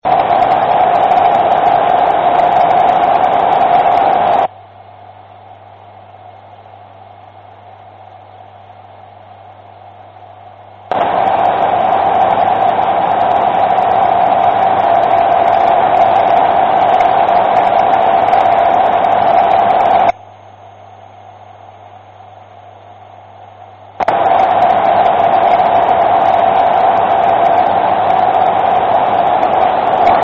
Zaj teszt antennával és az R-326 saját zaja – hangban és képben elmondva:
A sávzaj (14 MHz-en felvéve, BW=cca 500 Hz) jól szemlélteti a nagyvárosi elektromágneses szmogot.
R-326_noise_test-1.mp3